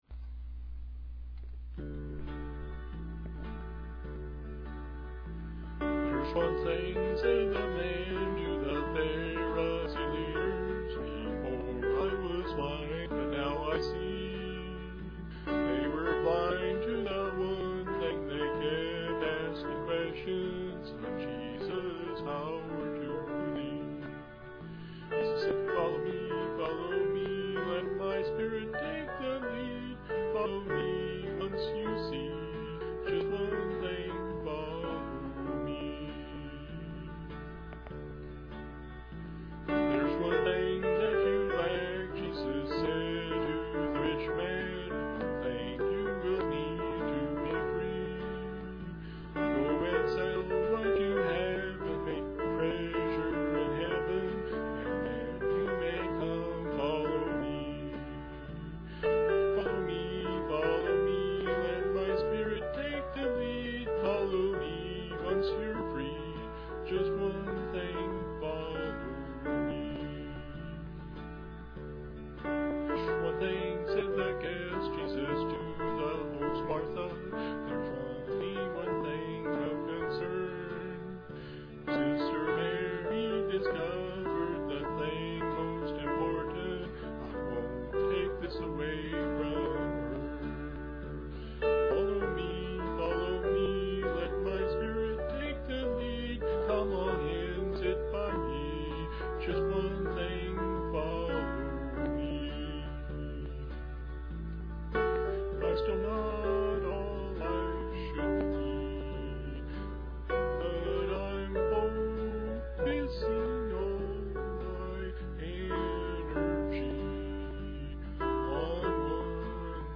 One Thing - mp3 - (a rough guide to how the words go)